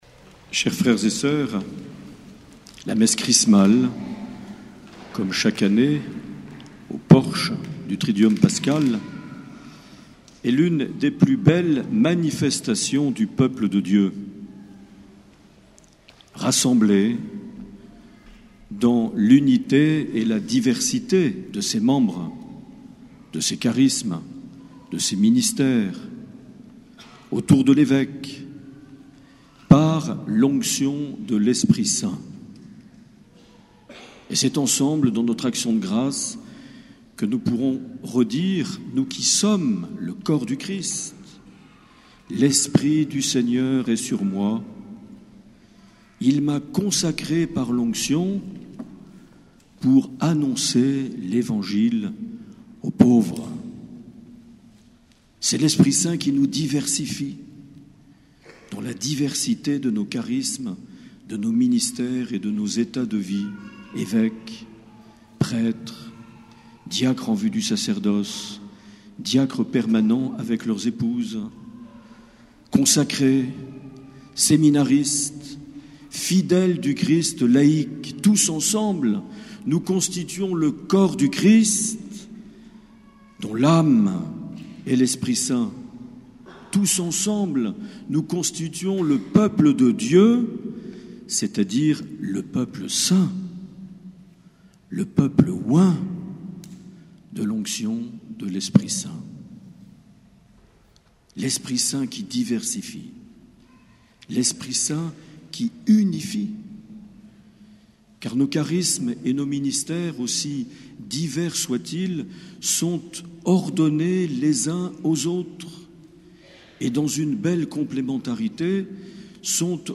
1er avril 2015 - Cathédrale de Lescar - Messe Chrismale
Les Homélies
Une émission présentée par Monseigneur Marc Aillet